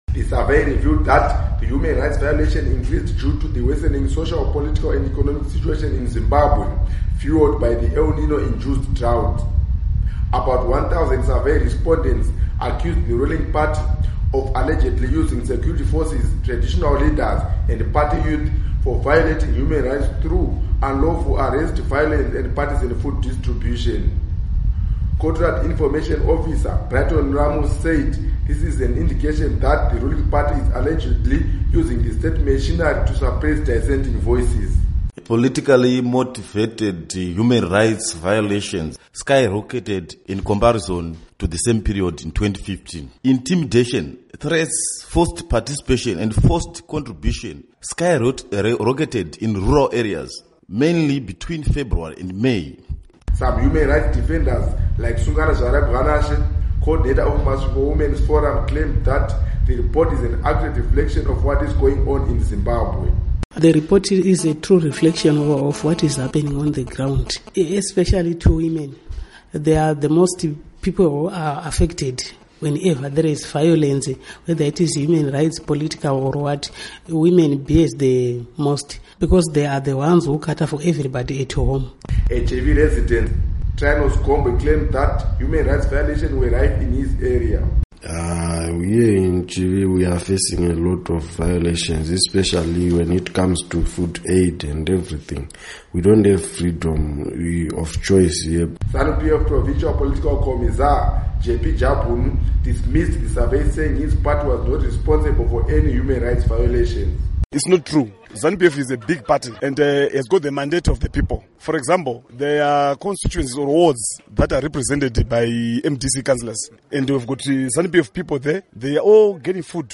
Report on Political Violence